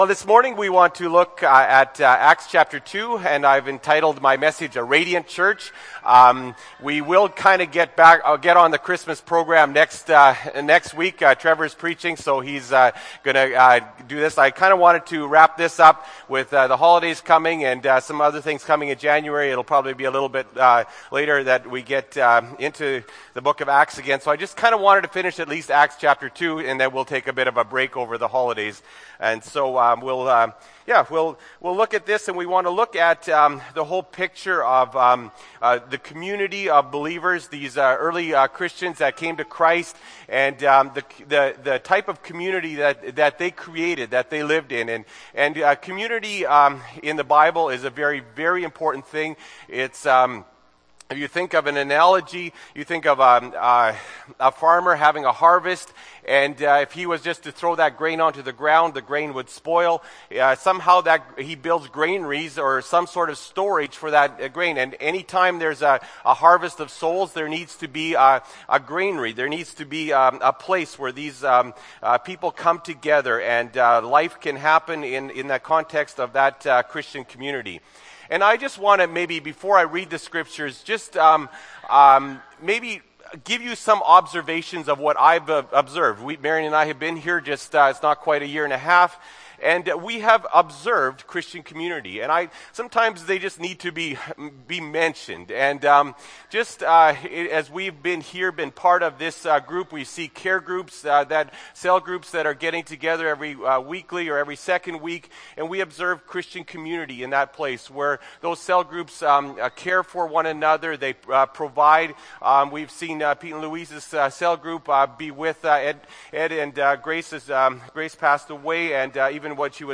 Dec. 8, 2013 – Sermon